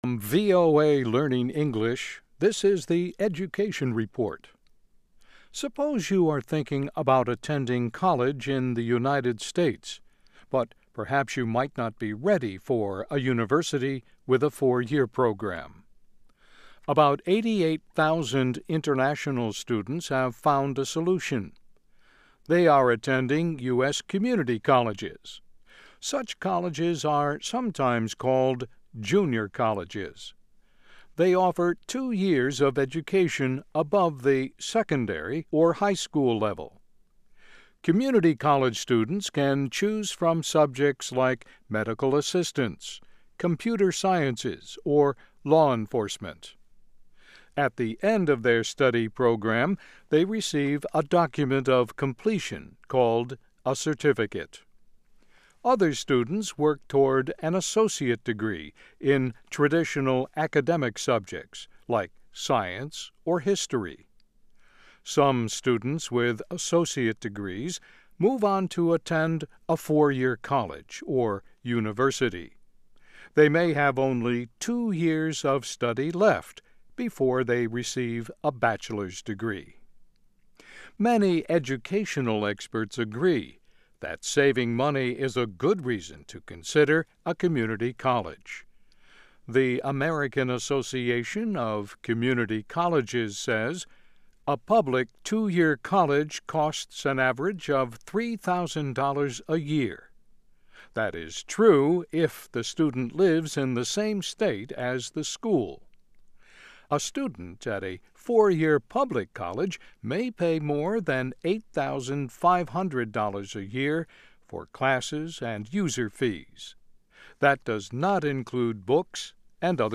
Learn English as you read and listen to a weekly show about education, including study in the U.S. Our stories are written at the intermediate and upper-beginner level and are read one-third slower than regular VOA English.